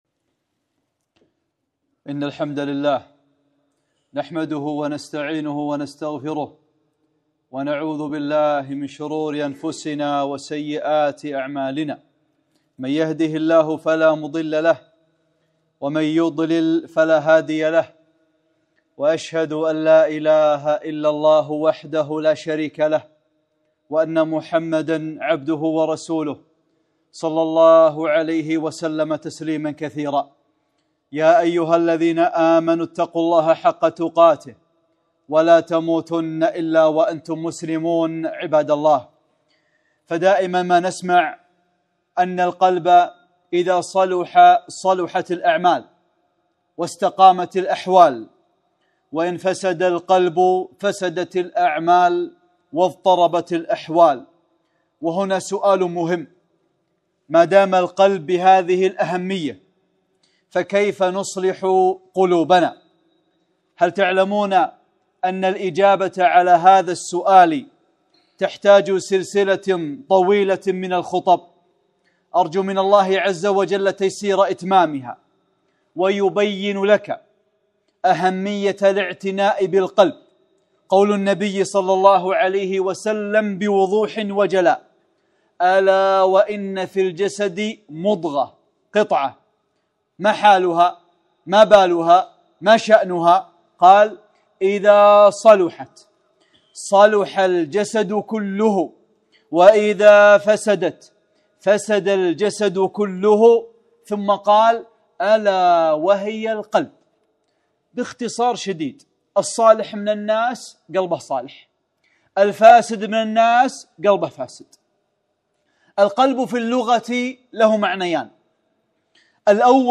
خطبة - أهمية الاعتناء بالقلب | أعمال القلوب